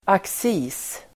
Uttal: [aks'i:s]